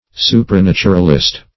Search Result for " supranaturalist" : The Collaborative International Dictionary of English v.0.48: Supranaturalist \Su`pra*nat"u*ral*ist\, n. A supernaturalist.